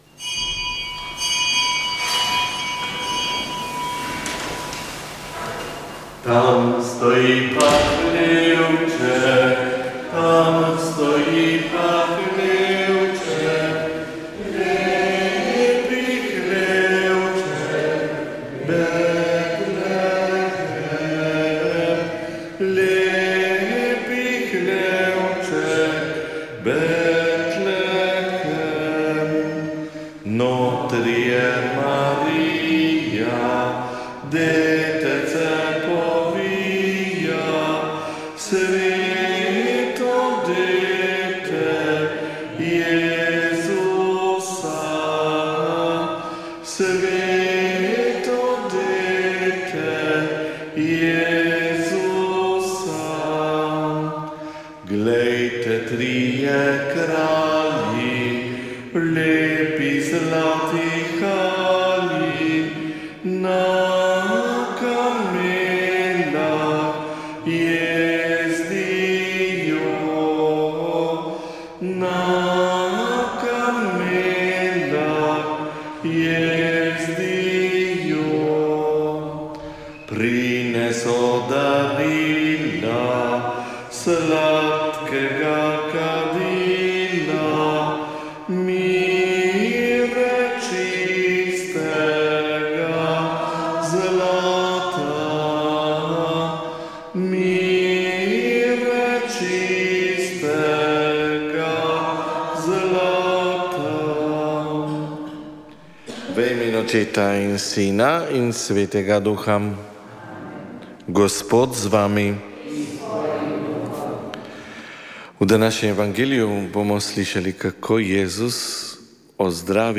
Sveta maša
Sv. maša iz cerkve Marijinega oznanjenja na Tromostovju v Ljubljani 19. 12.